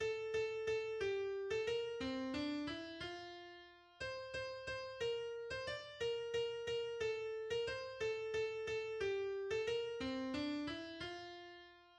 deutsches Lied (Kanon)
Melodie